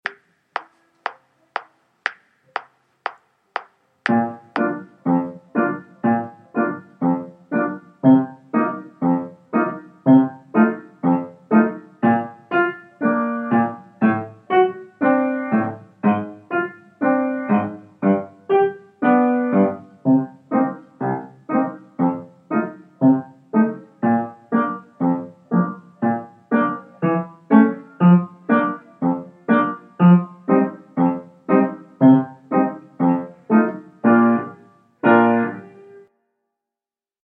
Pierrot Piano Only